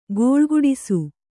♪ gōḷguḍisu